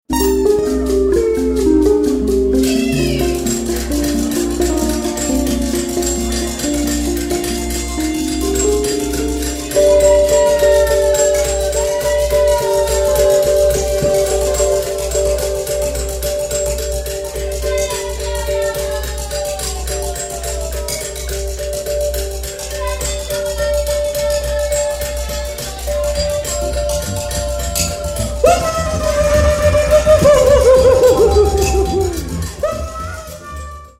Cello
Vocals, Perc.
Saxophone
Trumpet
Live aus dem Porgy&Bess Wien